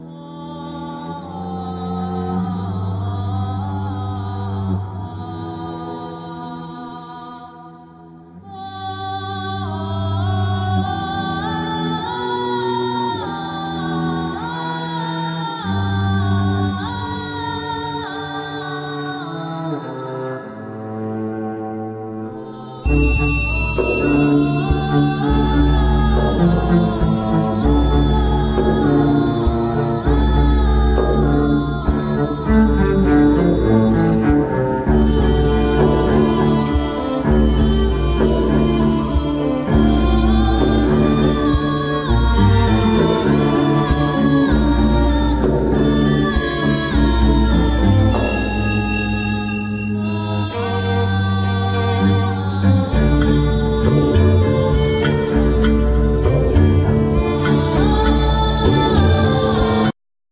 Vocal
Flute,Travesera,Gaita
Violin,Mandolin
Ud,Buzuki,Zanfona,Percussions